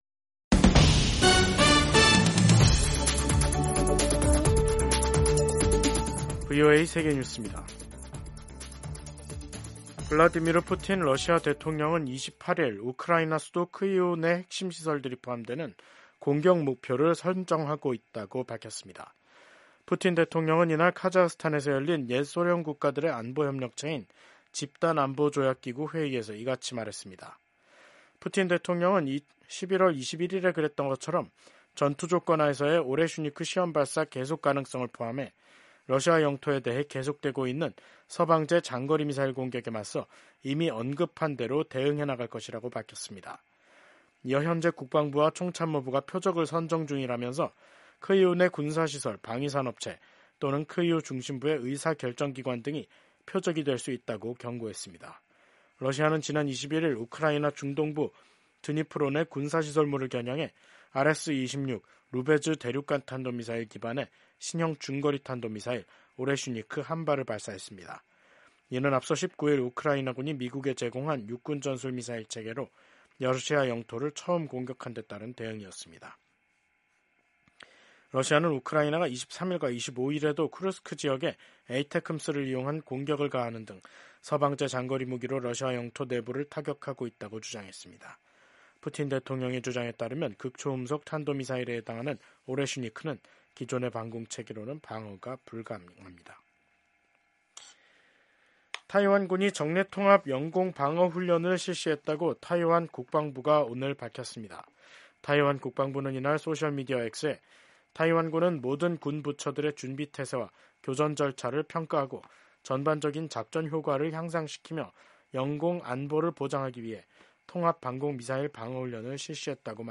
세계 뉴스와 함께 미국의 모든 것을 소개하는 '생방송 여기는 워싱턴입니다', 2024년 11월 28일 저녁 방송입니다. 지난주 핵 사찰 문제로 국제원자력기구(IAEA)와 마찰을 빚었던 이란이 영국, 프랑스, 독일 등 유럽 3개국과 29일 스위스 제네바에서 직접 회담합니다. 미국과 중국이 수감자 3명을 맞교환했다고 양국 정부가 확인했습니다. 국제형사재판소(ICC) 검사장이 미얀마 군사정권 수장에 대한 체포영장을 청구했습니다.